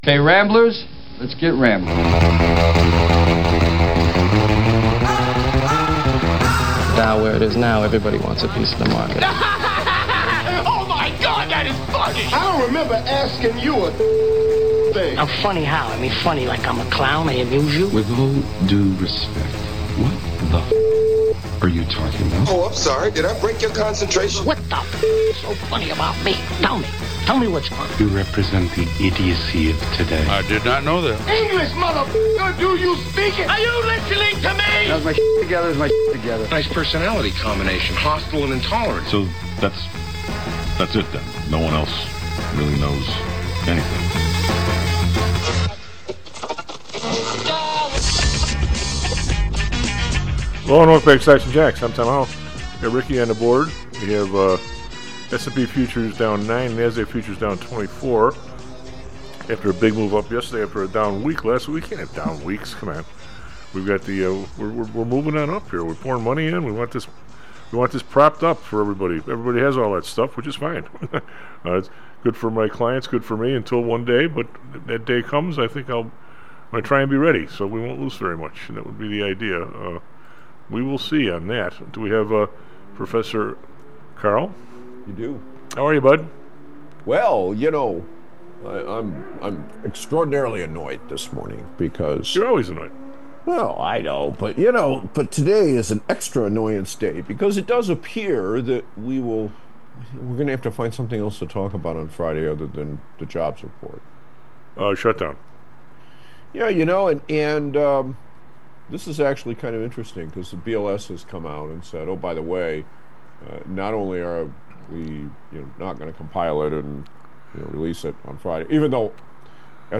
A talk show focusing on business and real market analysis, “Stocks and Jocks” breaks valuable news at the top of the trading day. This sometimes-irreverent show is just the thing for investors who need information about what to expect from Wall Street.